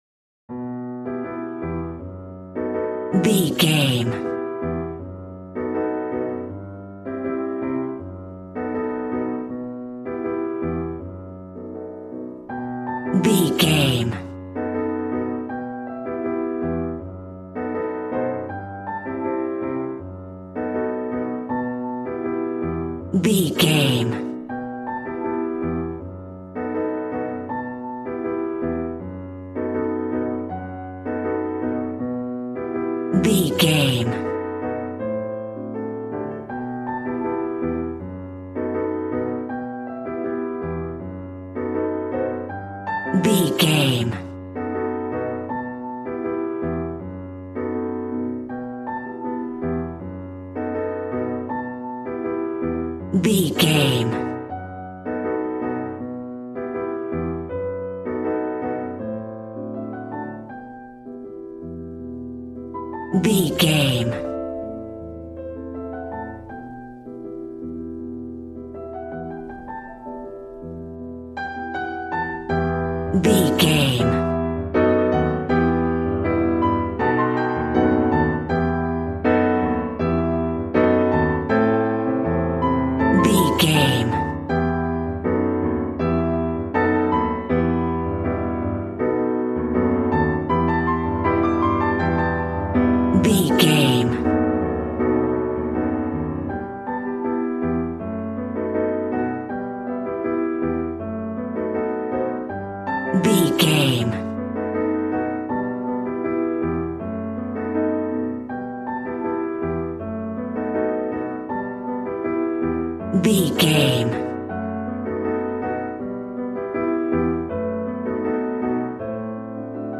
Aeolian/Minor
passionate
acoustic guitar